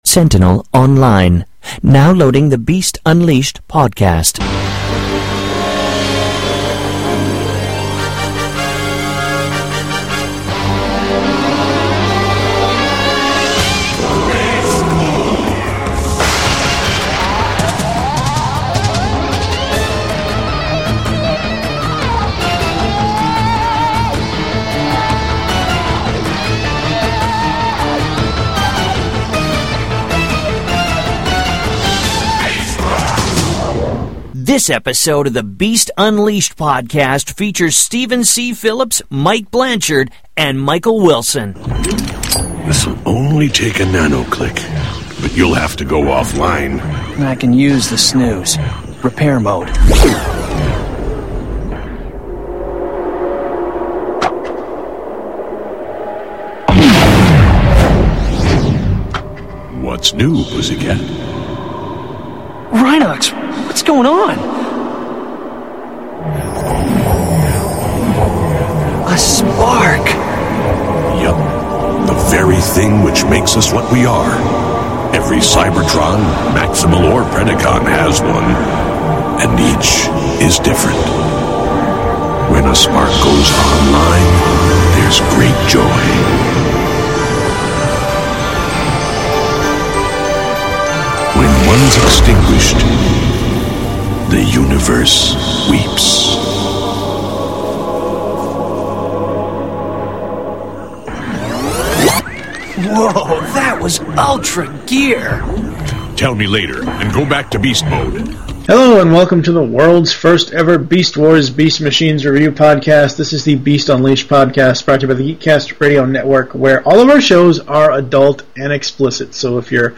This will be a more light hearted go round.